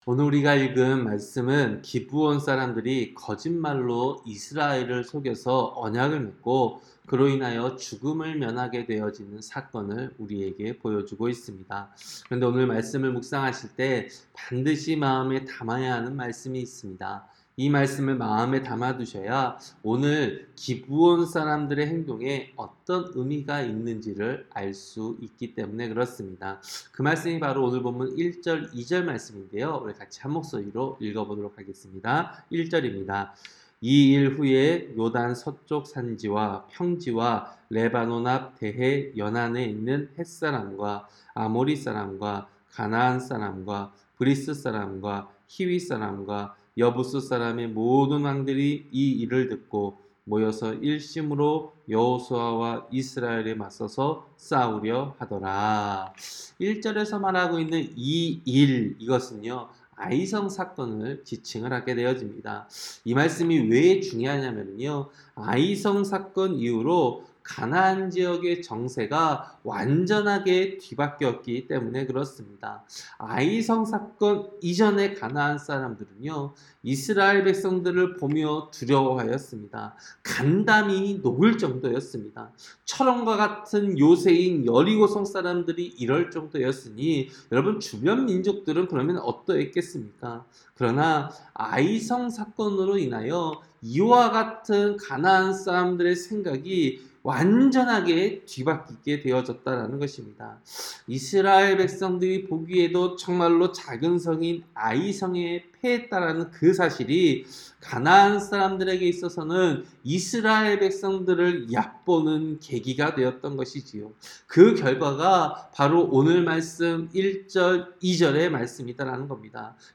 새벽설교-여호수아 9장